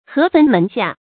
注音：ㄏㄜˊ ㄈㄣˊ ㄇㄣˊ ㄒㄧㄚˋ
河汾門下的讀法